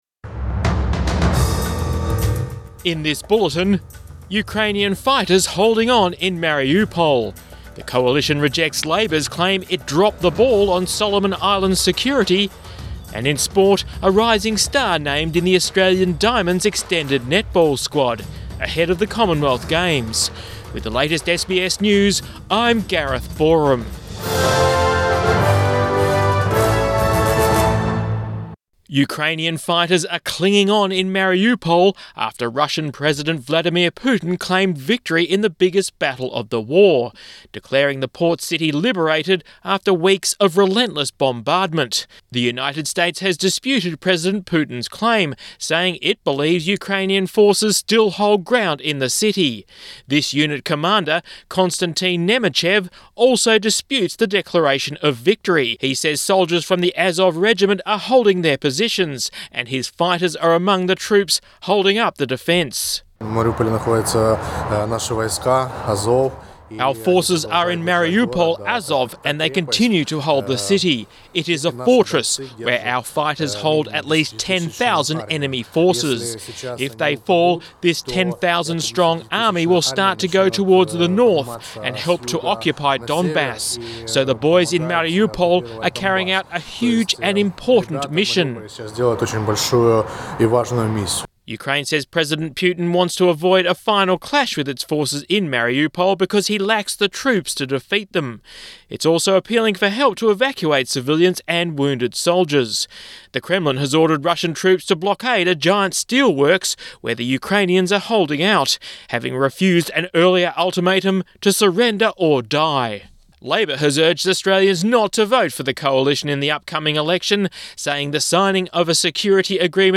PM bulletin 22 April 2022